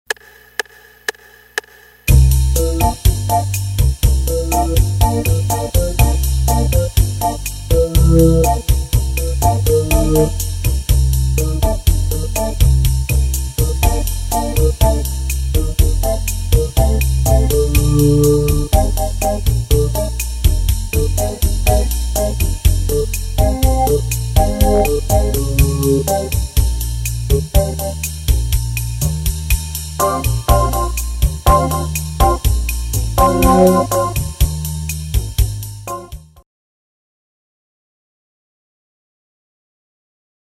Dominant @ 120 bpm 4 measures per key